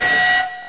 alarm01.wav